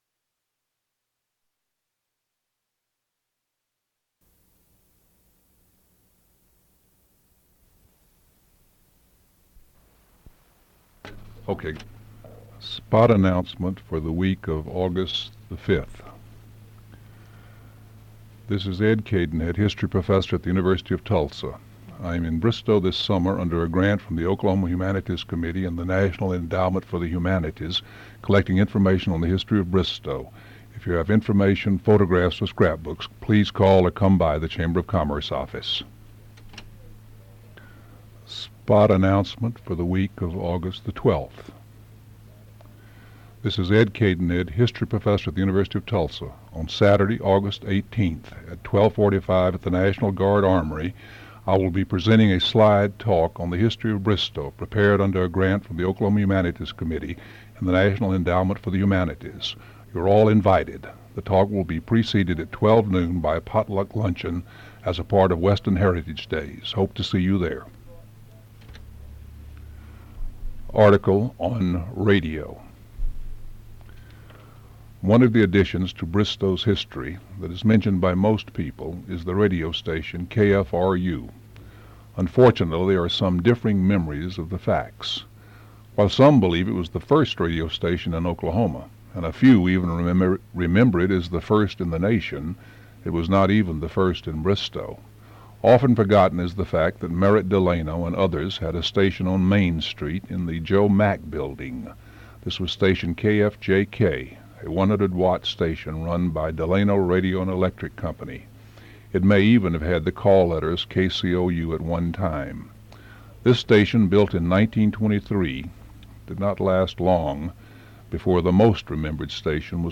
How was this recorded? Original Cassette Tape Location: OHP-0028-01 Side A at 00:00 to 25:47